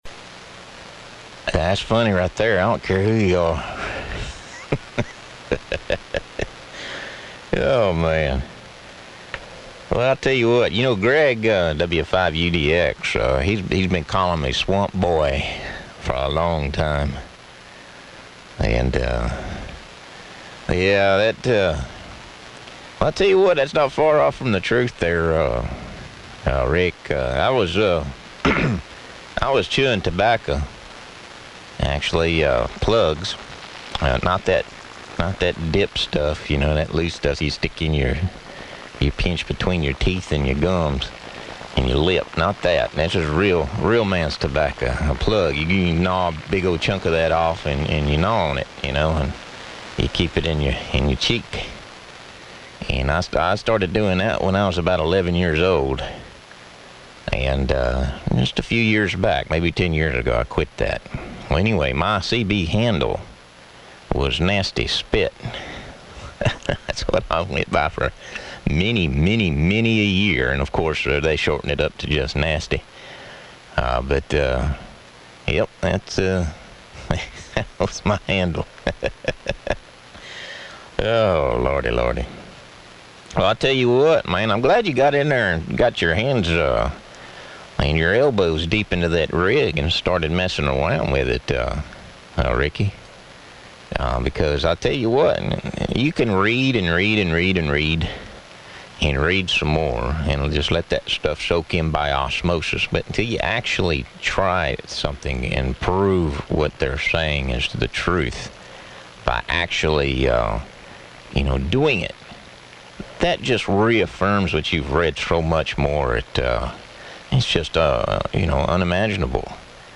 Well, here is one from 15 meters, but the signals were not that great.
Also the recording station had some local QRN.